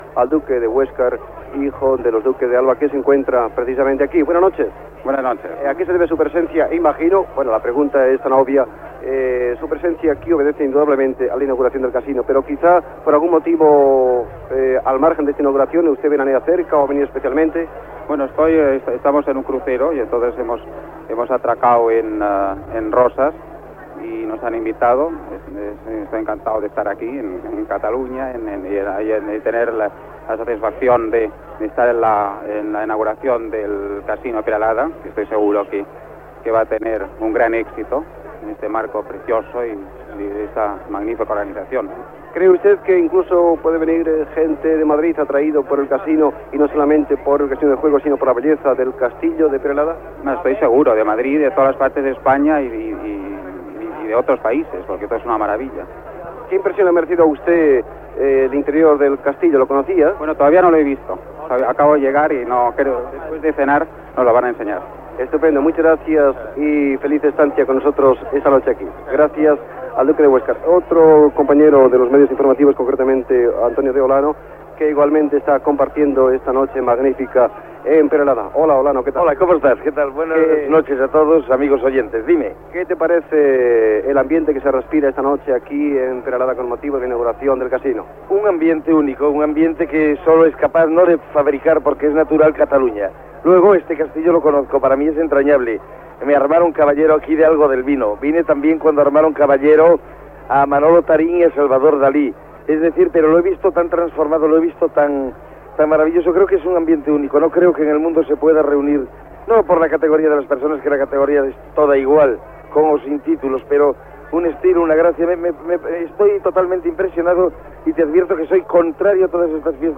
Especial inauguració del Casino de Peralada.
Entreteniment